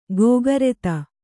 ♪ gōgareta